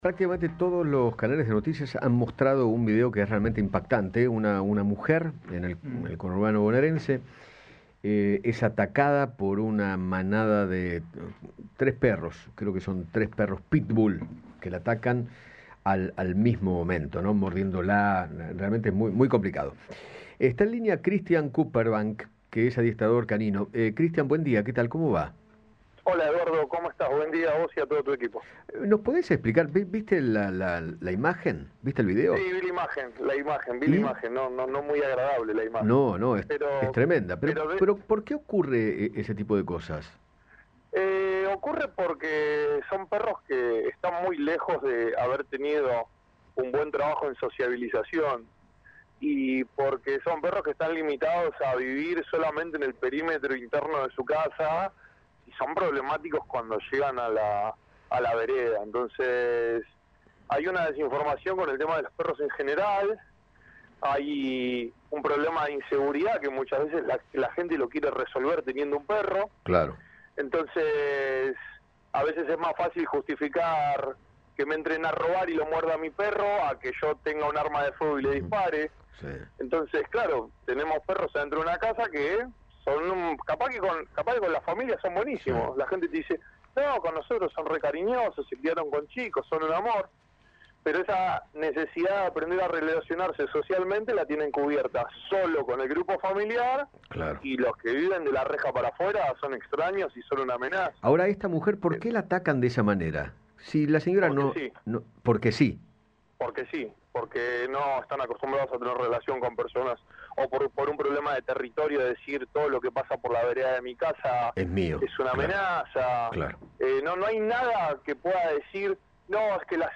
adiestrador canino